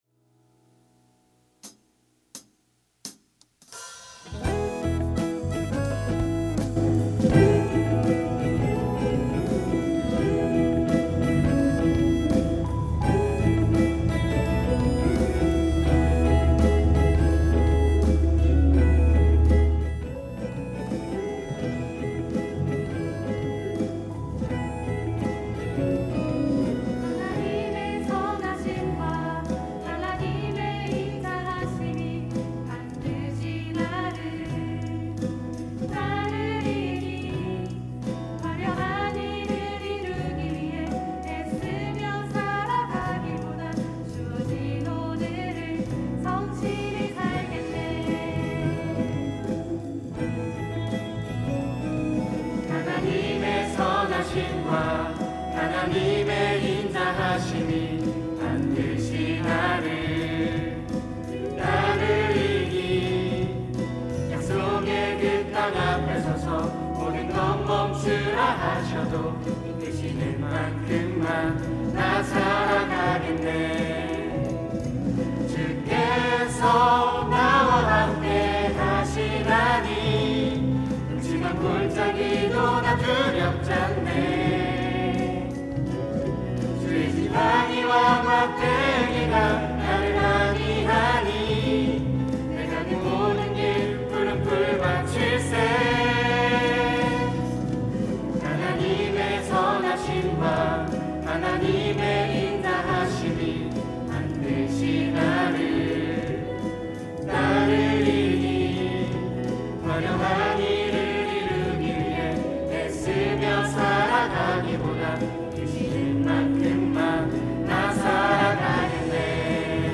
특송과 특주 - 주어진 오늘을